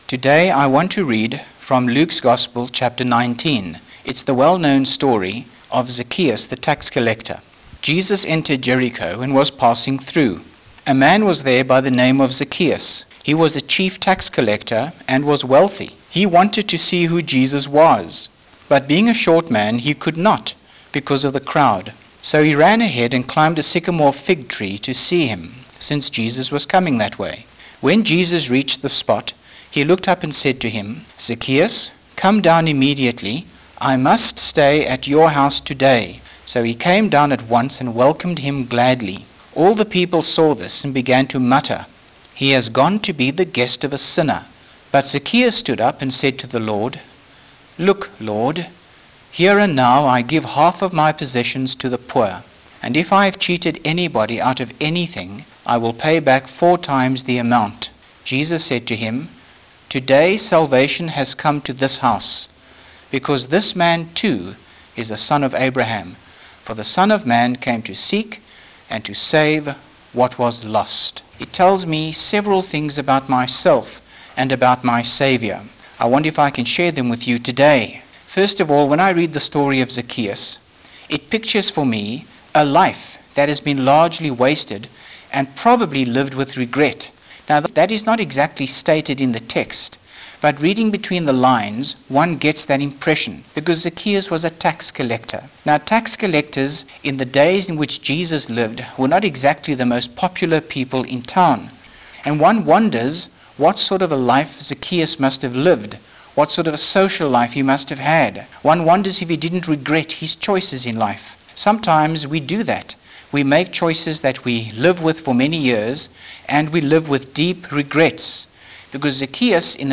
5 minute talk